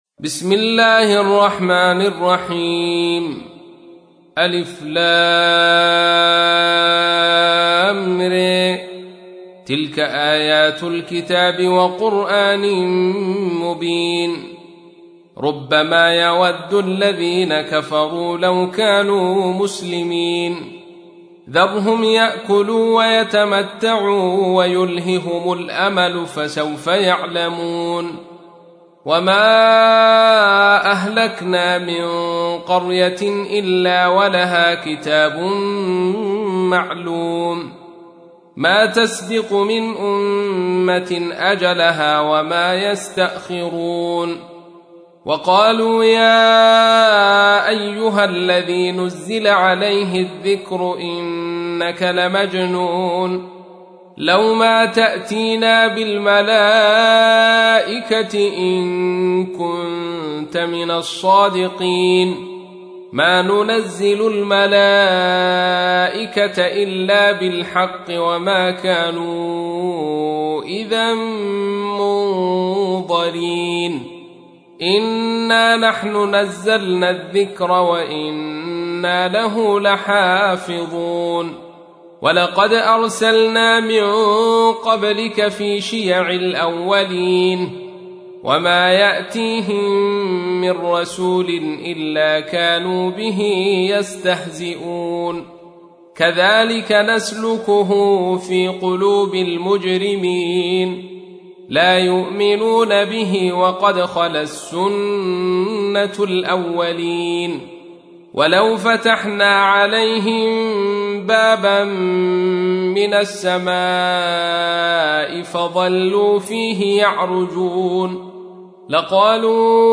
تحميل : 15. سورة الحجر / القارئ عبد الرشيد صوفي / القرآن الكريم / موقع يا حسين